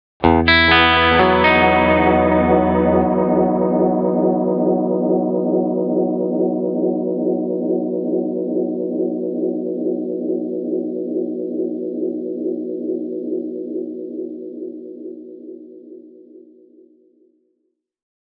Tältä Flashback X4:n efektityypit kuulostavat:
Analog with Mod
analog-with-mod.mp3